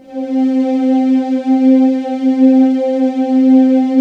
Index of /90_sSampleCDs/USB Soundscan vol.28 - Choir Acoustic & Synth [AKAI] 1CD/Partition C/10-HOOOOOO
HOOOOOO C3-R.wav